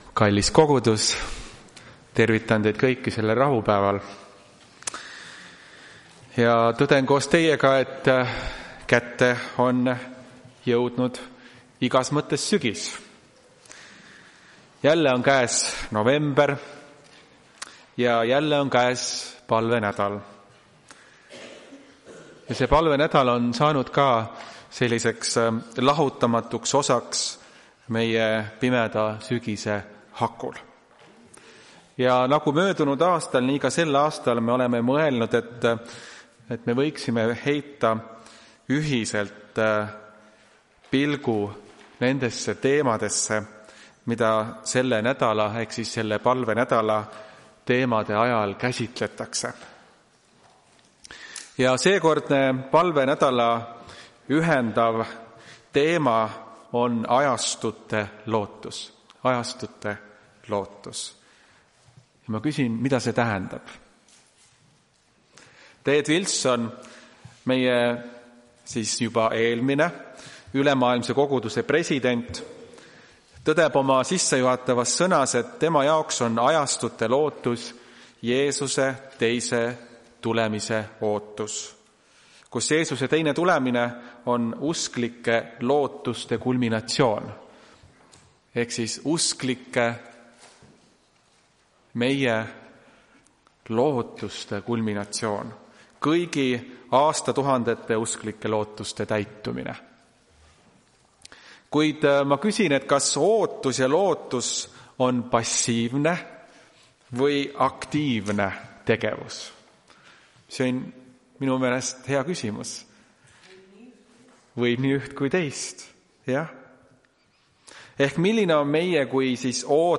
Tartu adventkoguduse 01.11.2025 teenistuse jutluse helisalvestis. Jutluse keskne tekst: Hs 47:9